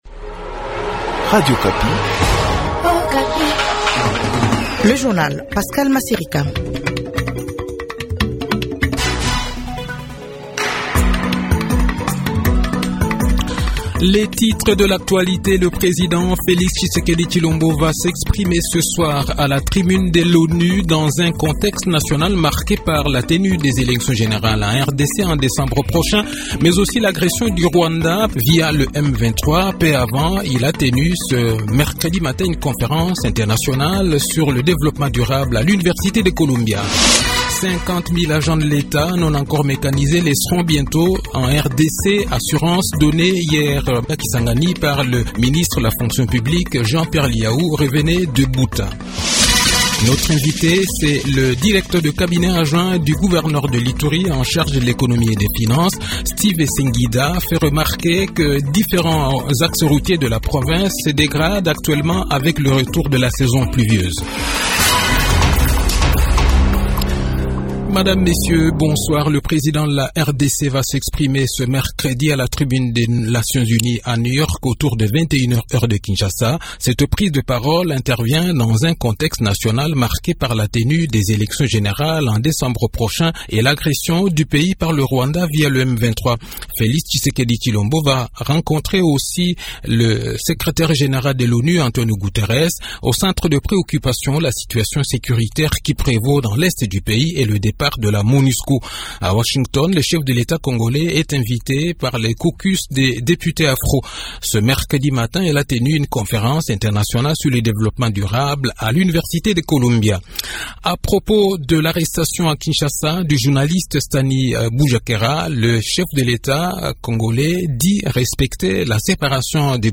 Le journal de 18 h, 20 septembre 2023